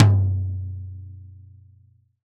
Acoustic Mid Tom 01.wav